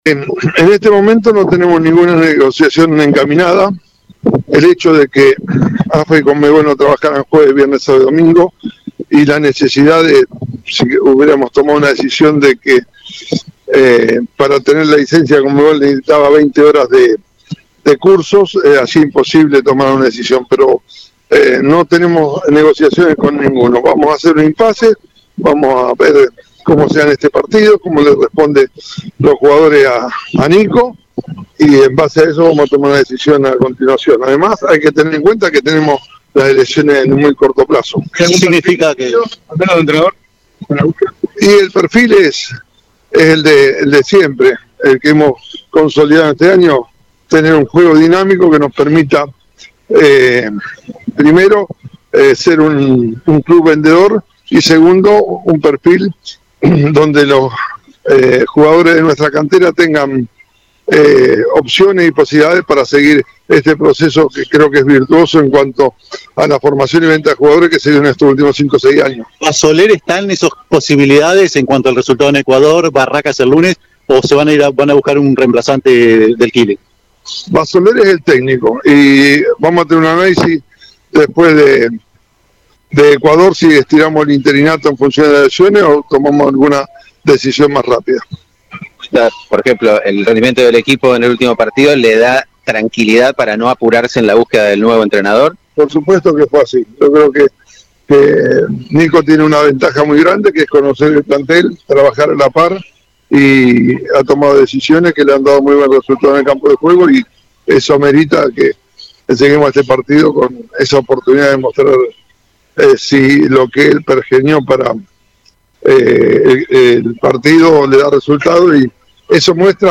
habló con la prensa santafesina en el Aeropuerto Sauce Viejo, antes de la partida a Ecuador.